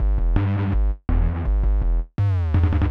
Free Bass